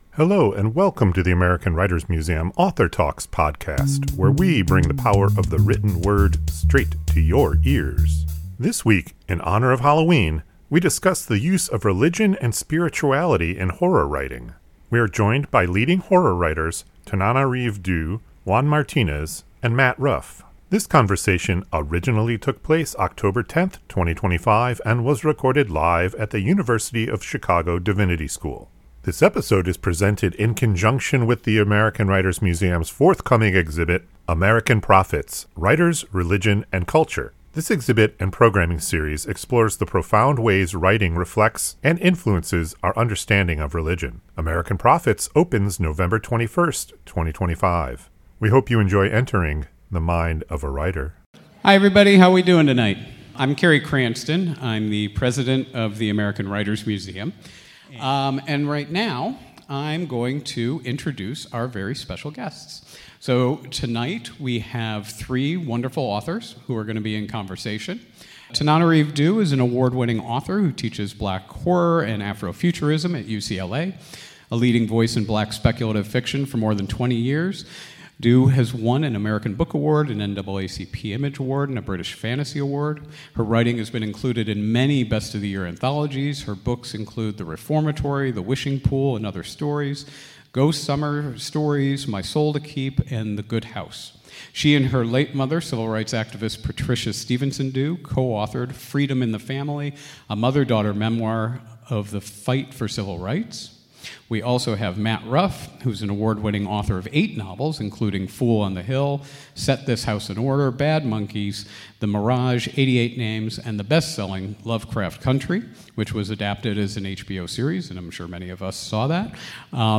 This conversation originally took place October 10, 2025 and was recorded live at the University of Chicago Divinity School.